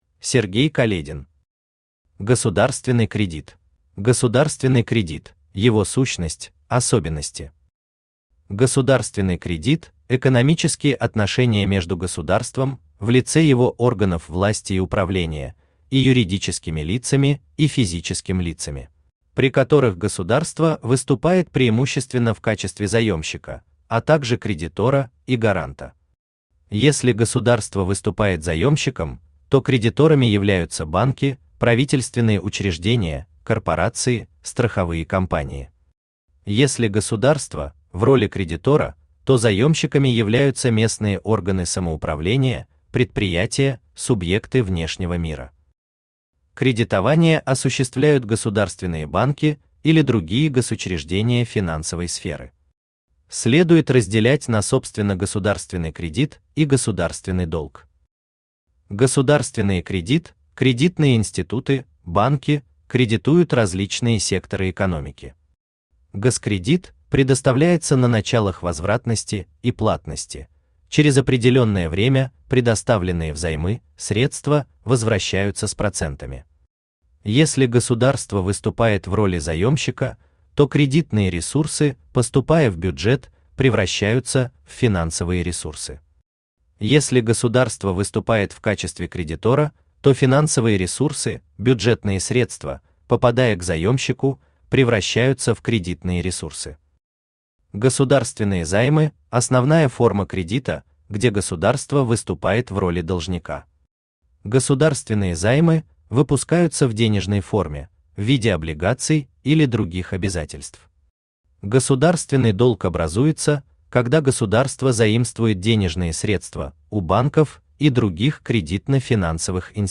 Аудиокнига Государственный кредит | Библиотека аудиокниг
Aудиокнига Государственный кредит Автор Сергей Каледин Читает аудиокнигу Авточтец ЛитРес.